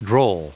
Prononciation du mot droll en anglais (fichier audio)
Prononciation du mot : droll